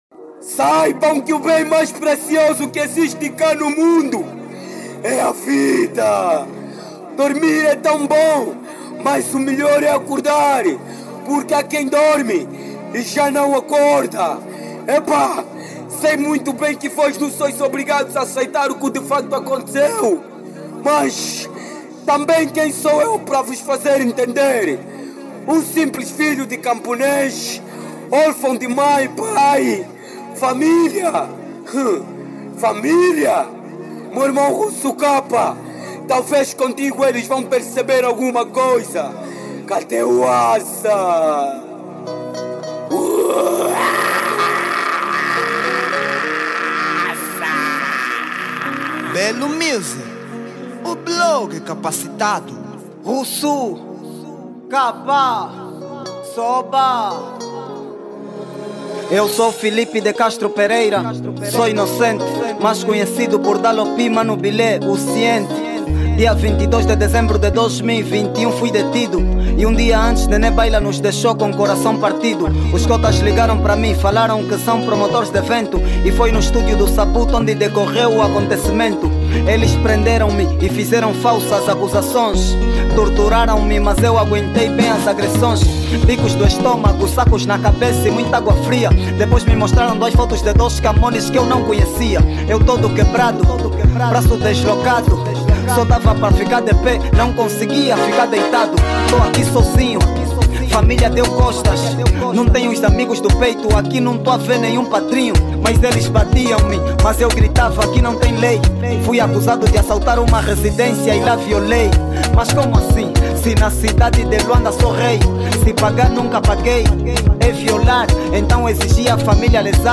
Género : Kuduro